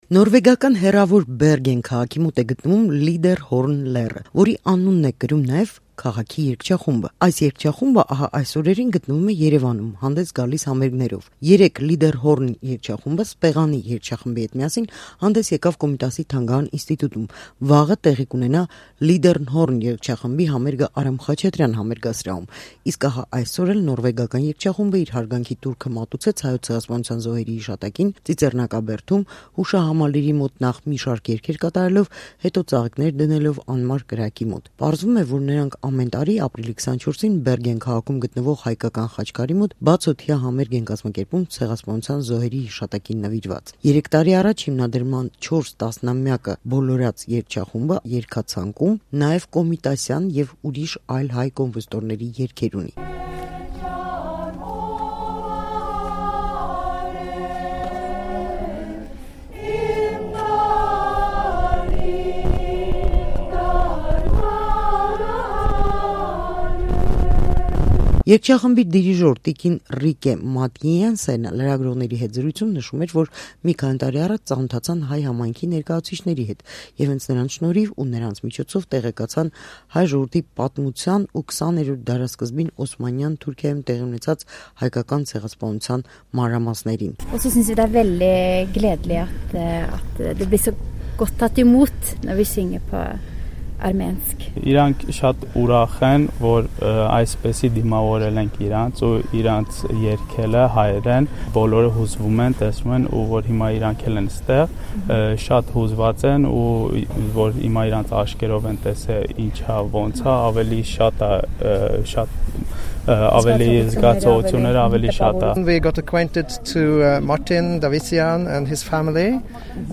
Նորվեգական երգչախումբը Ծիծեռնակաբերդում կատարեց Կոմիտասի ստեղծագործությունները